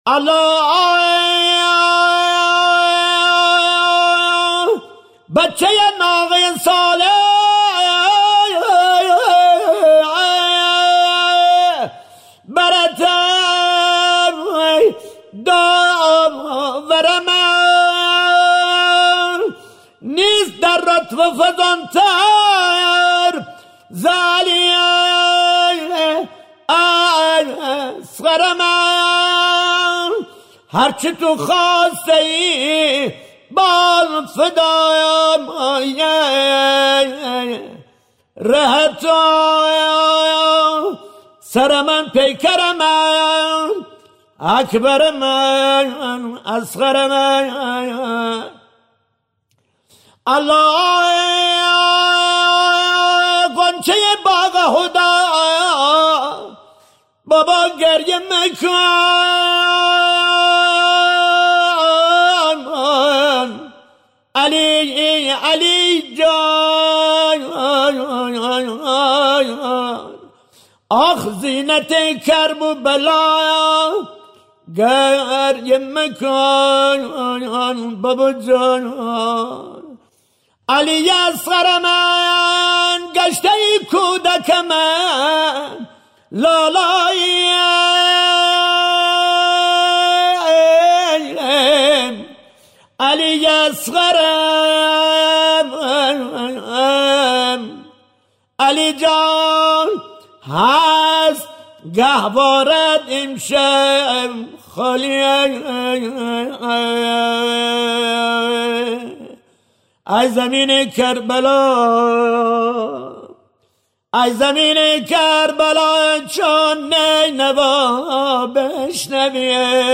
(بشنوید) تعزیه در گیلان